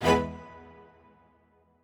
admin-leaf-alice-in-misanthrope/strings34_1_004.ogg at main